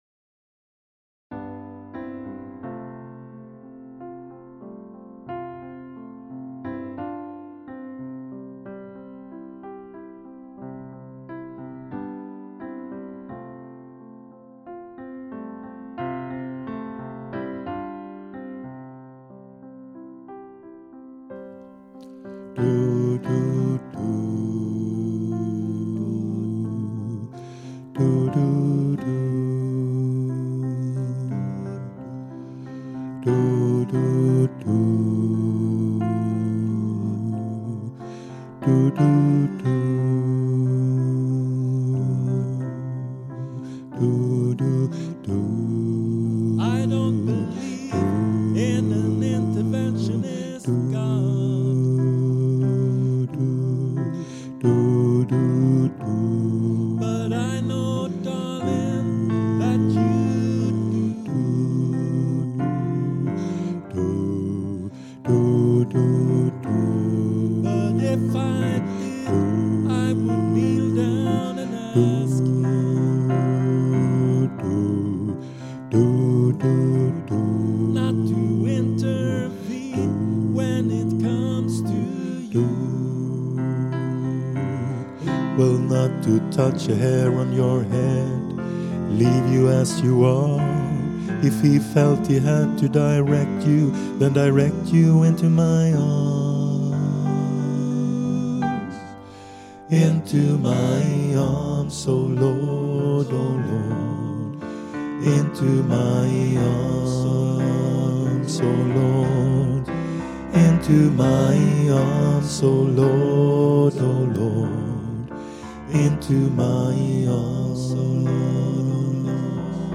Into My Arms - alt 2.mp3